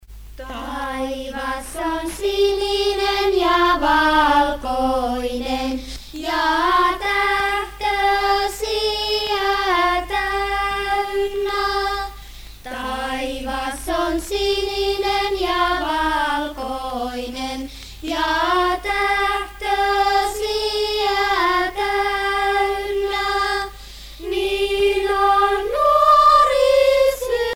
Festival folklorique de Matha 1980
Pièce musicale éditée